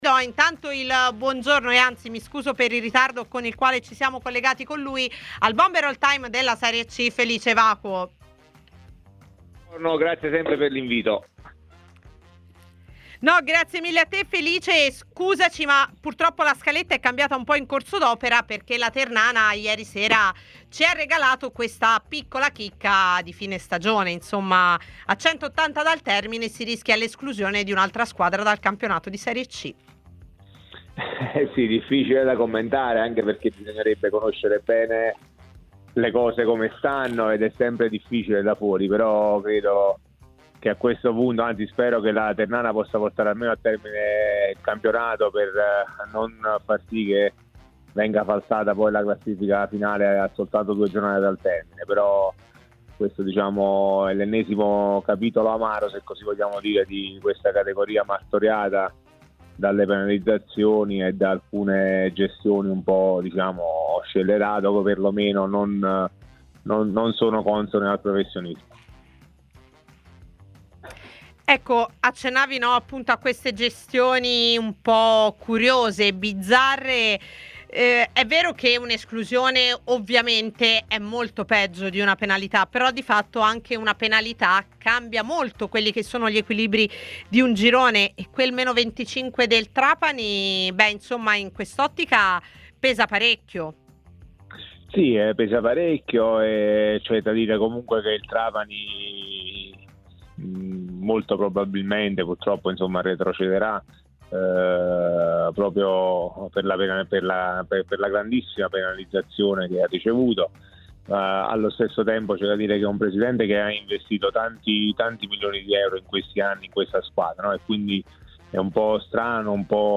Ai microfoni di TMW Radio, nel corso di A Tutta C, il miglior marcatore di tutti i tempi in Serie C Felice Evacuo ha parlato della possibile esclusione dal campionato della Ternana, dopo la messa in liquidazione della società: "È difficile da commentare, bisognerebbe conoscere bene come stiano le cose, ed è sempre complicato da fuori.